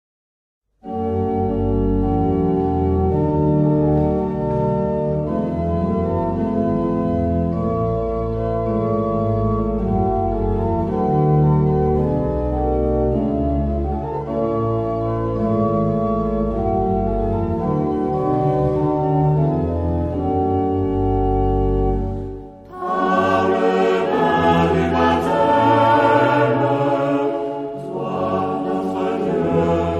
Genre-Style-Forme : Sacré ; Hymne (sacré)
Caractère de la pièce : calme
Type de choeur : SATB  (4 voix mixtes )
Tonalité : sol mineur